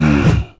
spawners_mobs_mummy_attack.5.ogg